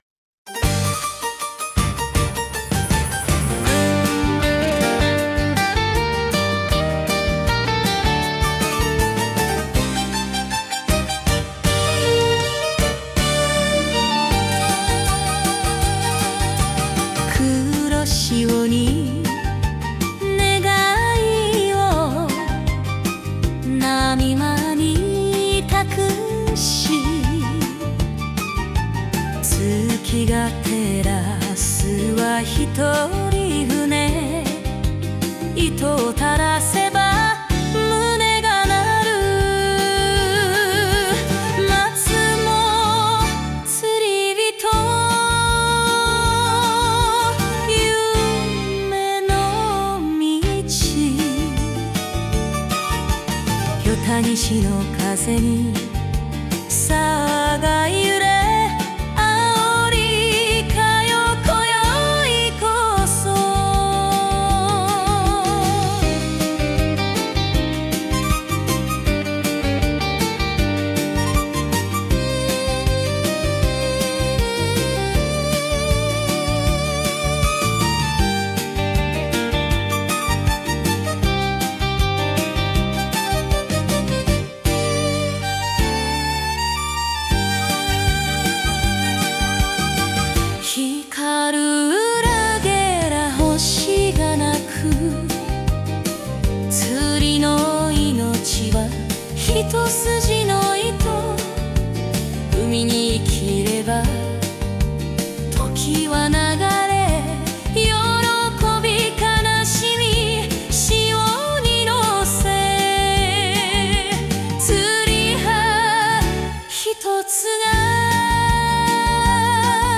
釣りの演歌を作ってみたので、一回聞いてみて下さい。
🎣 演歌釣り歌：黒潮に願いを